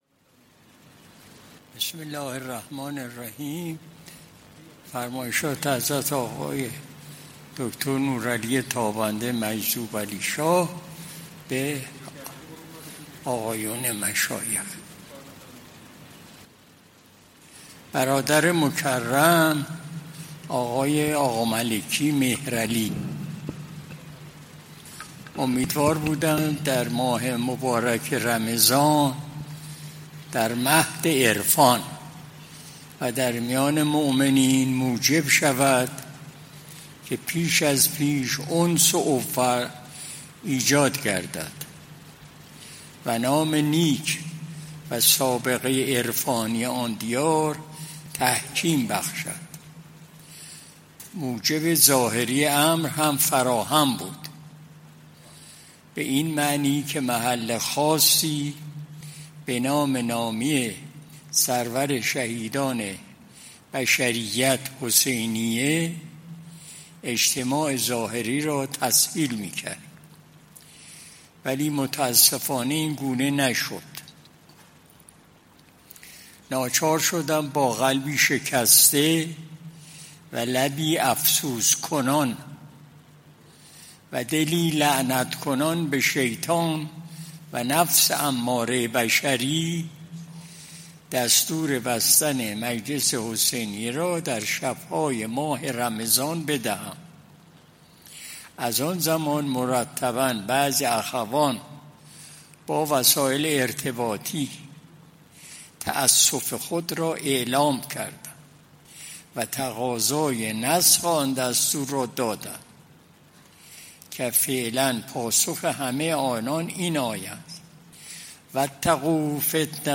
قرائت بیانیه‌های حضرت آقای مجذوبعلیشاه طاب‌ثراه